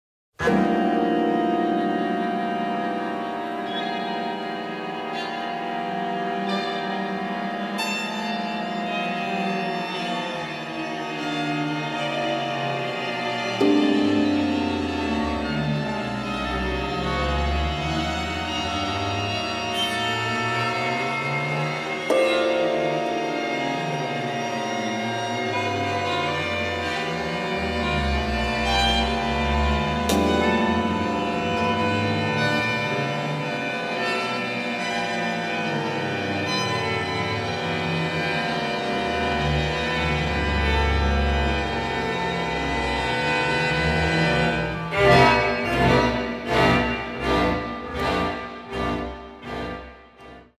score is a highly dramantic one